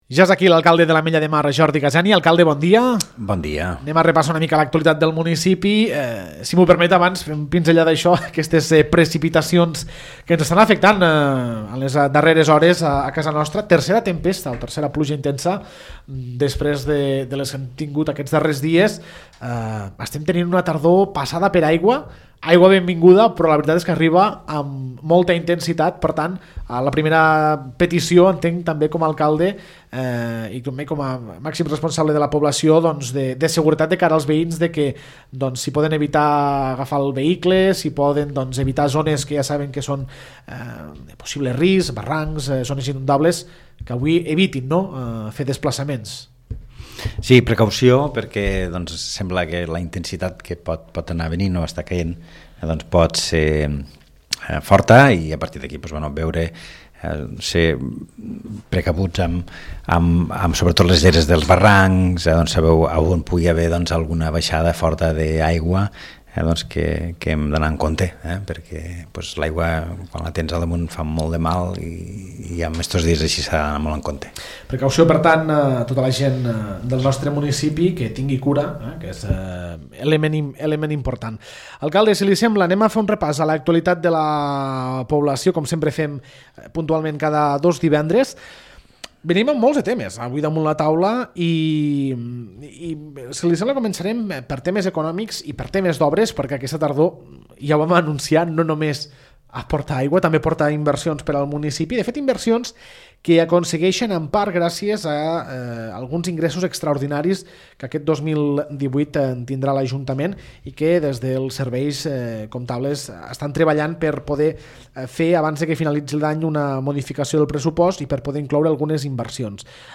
L’alcalde de l’Ametlla de Mar, Jordi Gaseni, repassa les notícies locals dels darrers dies al municipi.